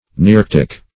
Search Result for " nearctic" : The Collaborative International Dictionary of English v.0.48: Nearctic \Ne*arc"tic\, a. [Neo + arctic.]
nearctic.mp3